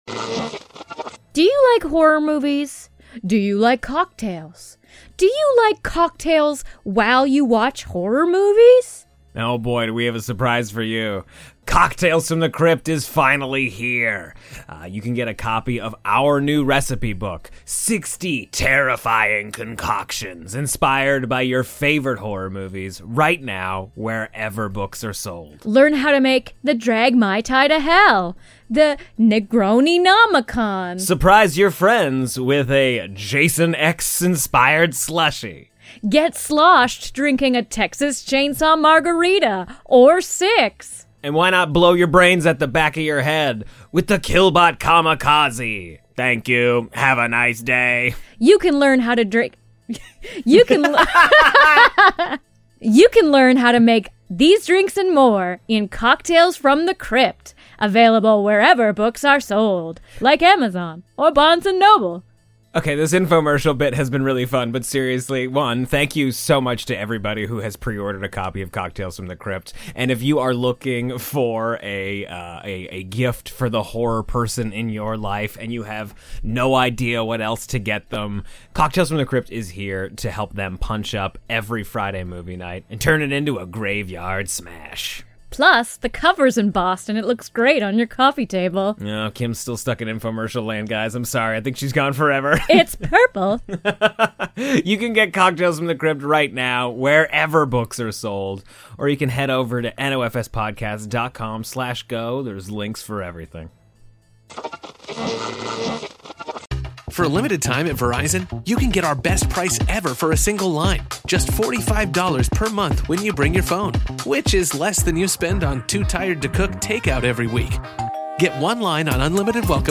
Forget the in-depth analyses and pull up a seat for lively, friendly banter that feels just like a post-movie chat with your best pals at the local bar.